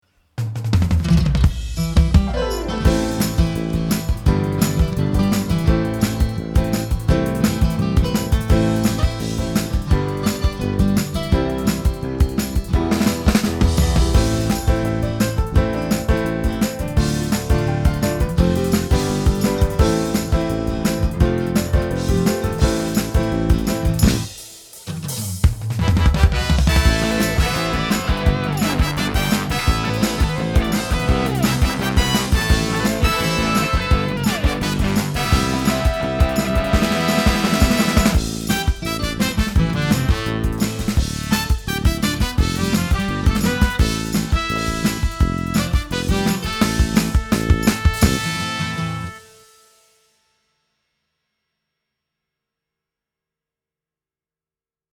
Instrumental Version